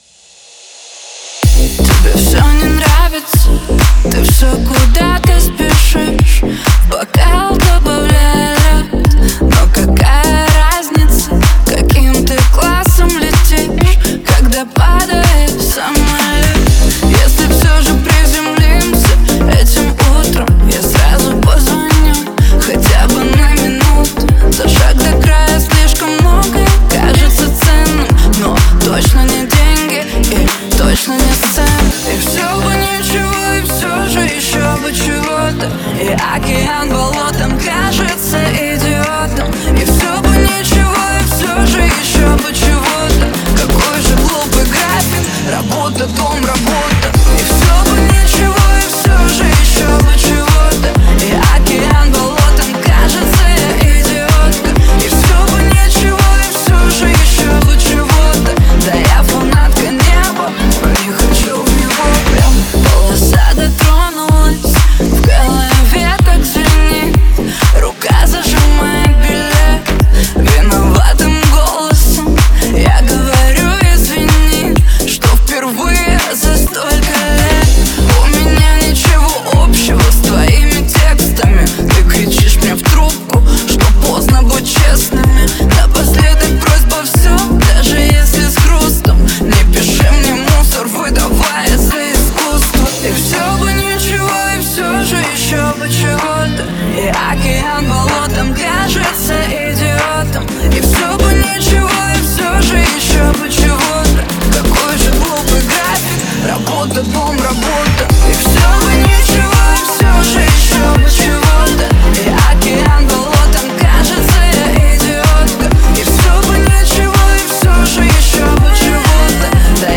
это яркий трек в жанре поп с элементами электронной музыки.